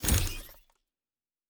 Sci-Fi Sounds / Doors and Portals / Door 7 Close.wav
Door 7 Close.wav